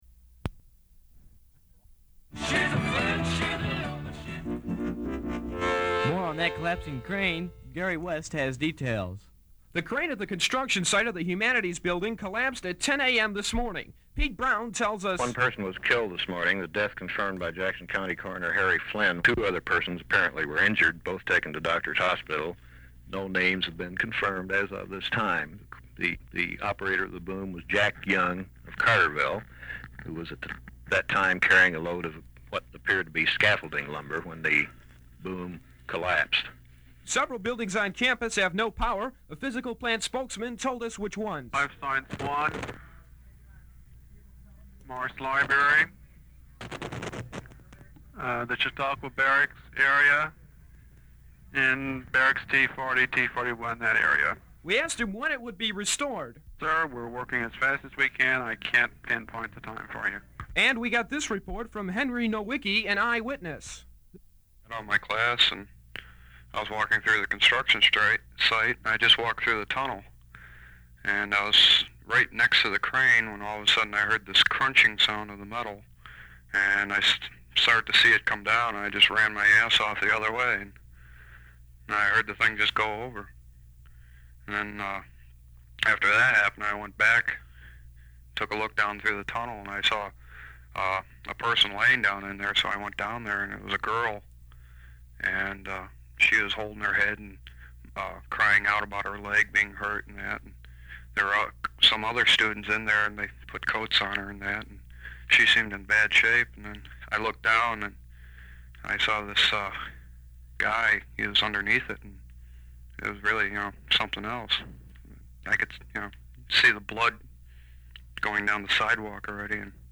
The 10:45 newscast began.